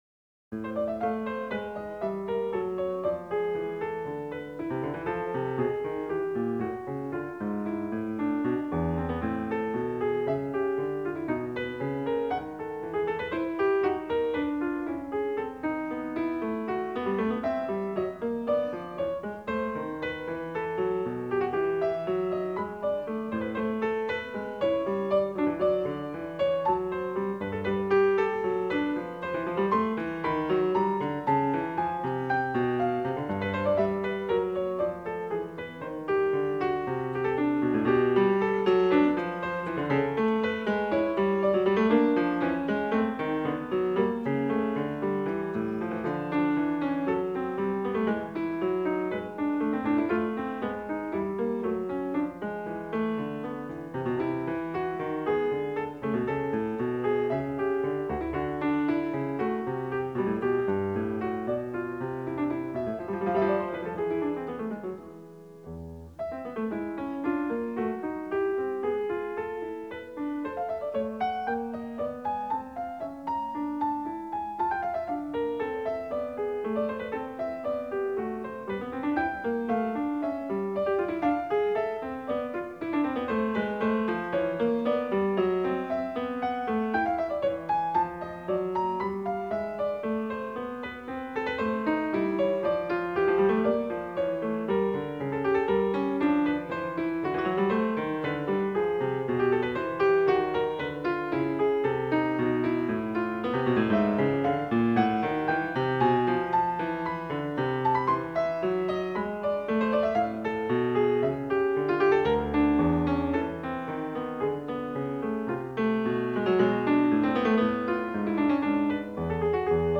23 Wtc Vol Ii, Prelude No 20 In A Minor, Bwv889